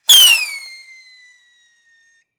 RocketFly.wav